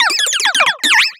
Catégorie:Cri Pokémon (Soleil et Lune) Catégorie:Cri de Candine